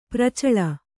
♪ pracaḷa